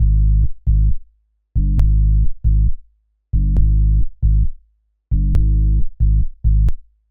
Jfx Bass.wav